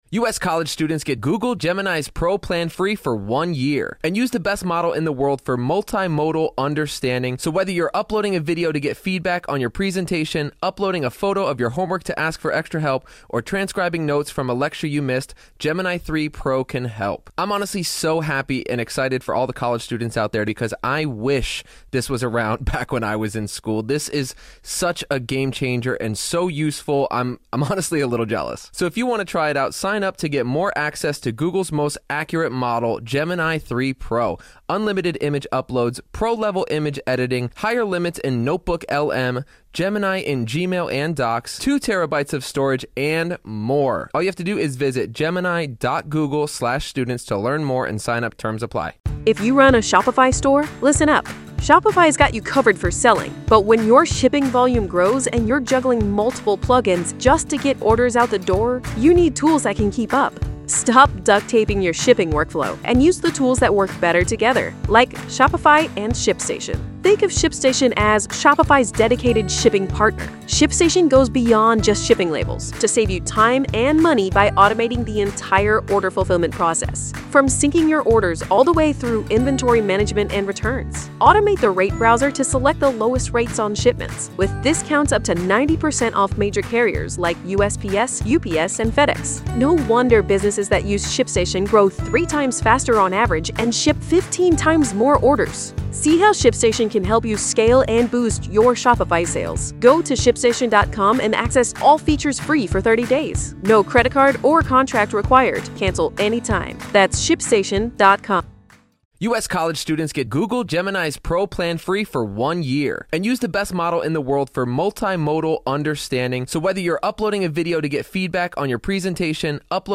This full-length interview